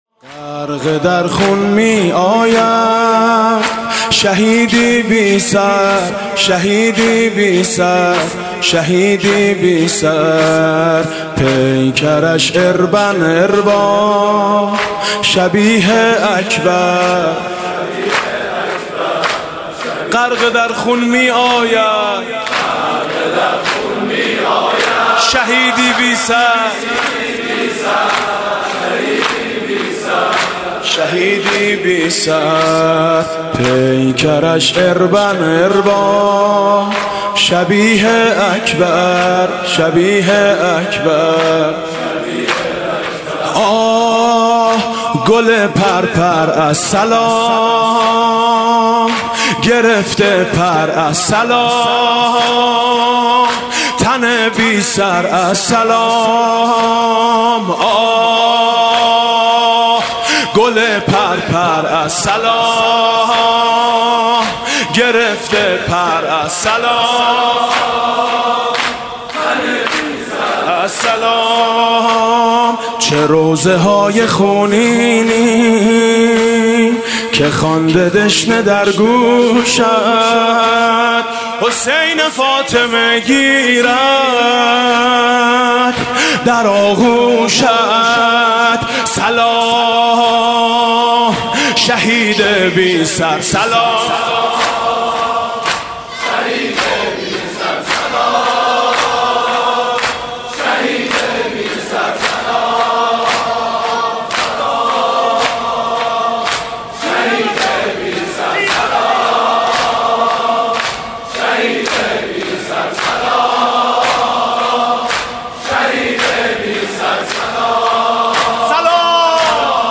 شب ششم محرم
دانشگاه امام صادق تهران